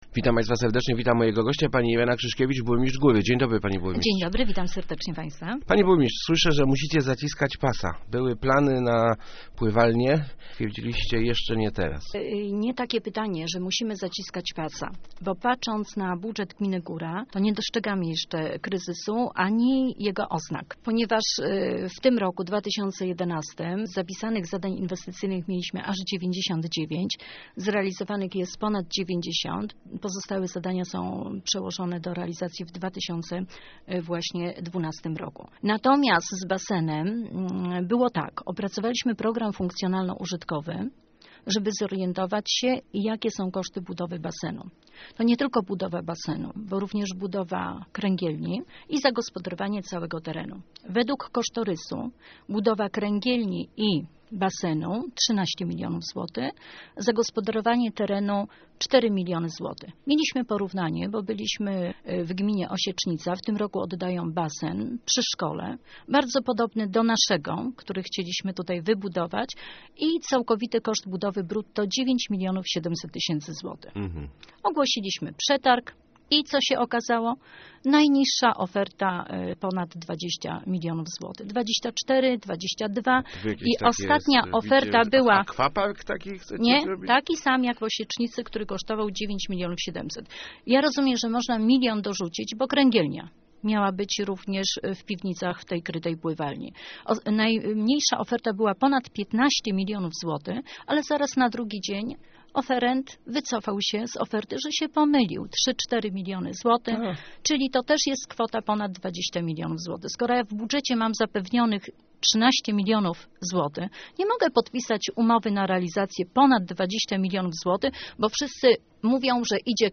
Pływalnia będzie, ale musimy zaczekać na lepsze czasy - mówiła w Rozmowach Elki burmistrz Góry Irena Krzyszkiewicz. Zaprzeczyła jednocześnie, że odłożenie budowy oznacza zaciskanie pasa.